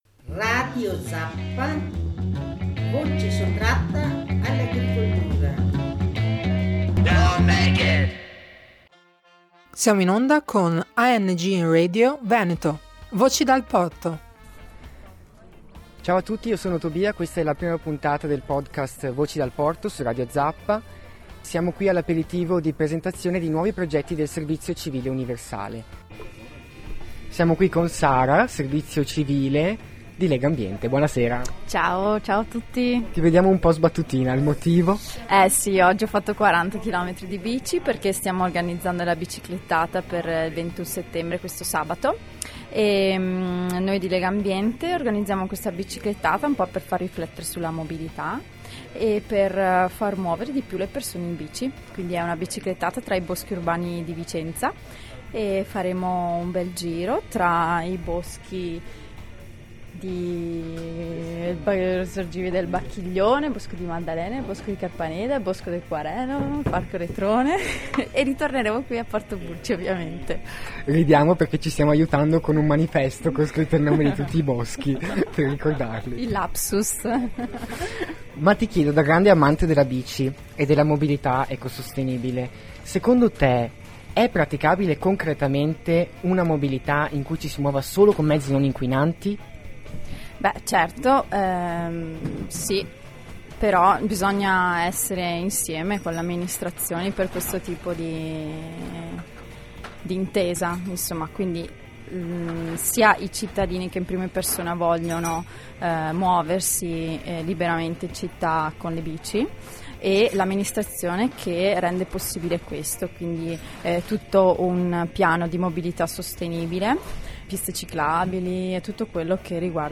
Il Servizio Civile a Vicenza passa per l’aperitivo. Cosa è cambiato in questi anni, come scegliere il proprio progetto, come sfruttare al meglio quest’opportunità di crescita: ne abbiamo parlato all’InfoSpritz di Porto Burci.